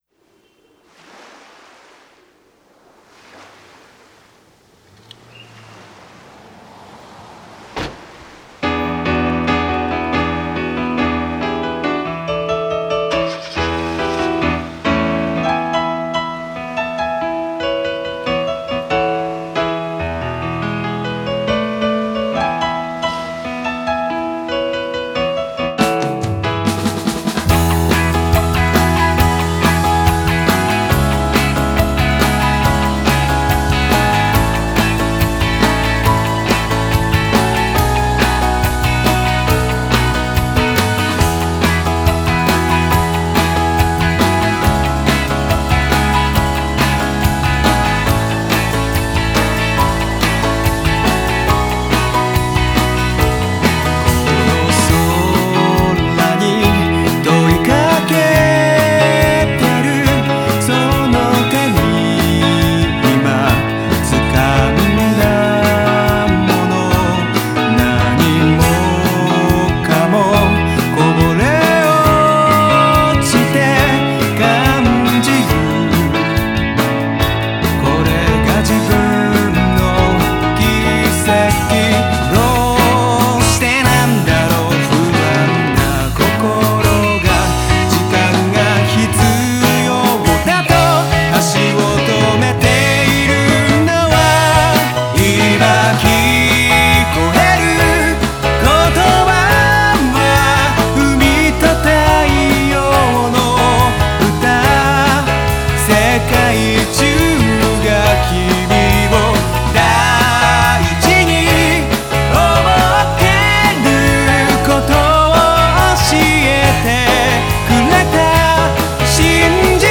試聴する（WAV）   　　　　　　　　AFTER MIX